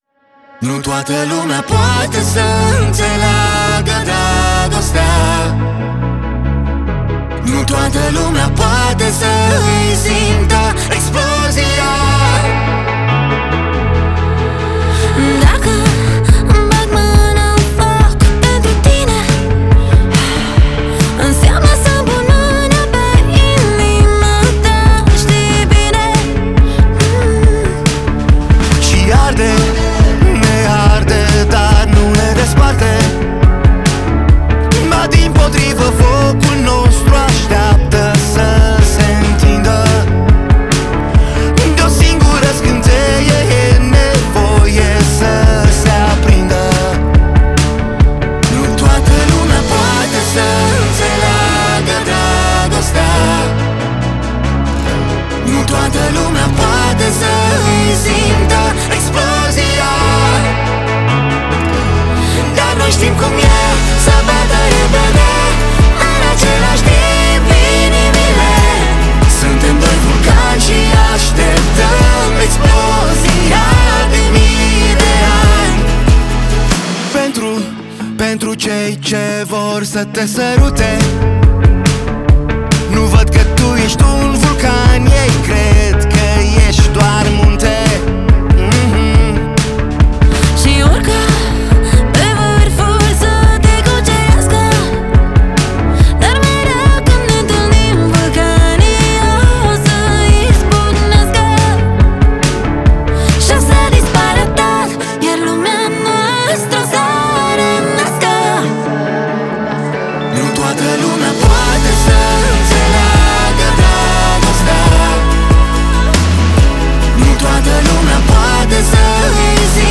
Muzica Usoara